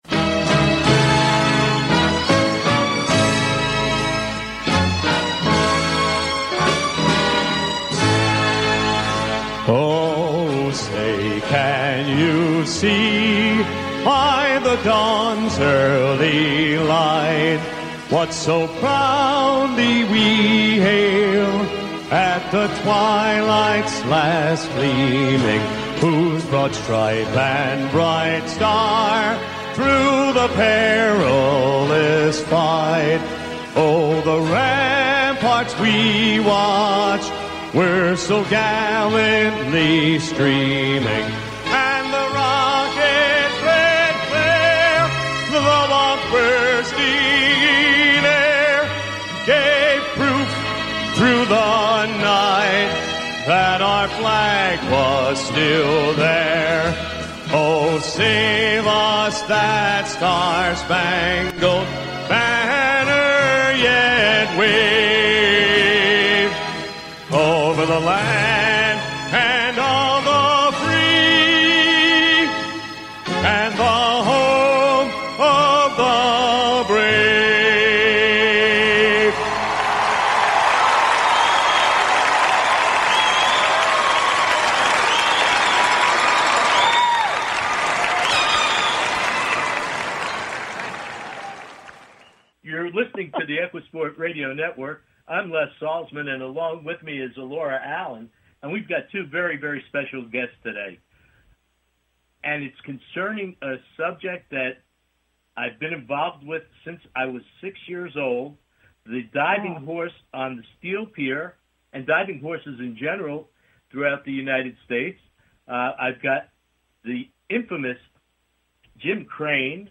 Talk Show Episode, Audio Podcast, EquiSport News